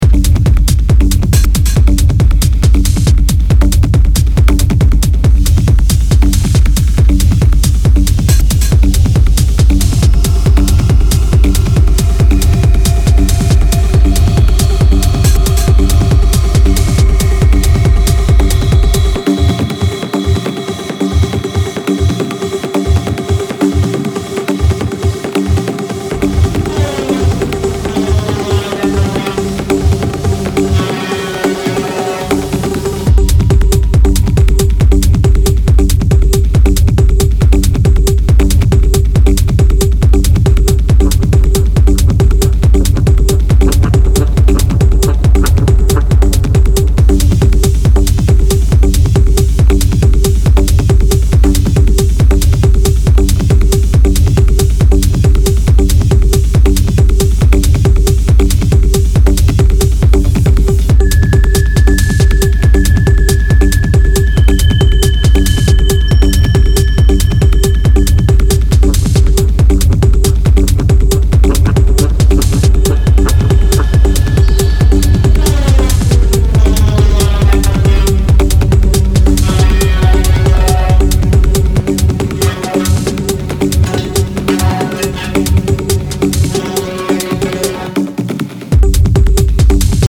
conceptual new techno EP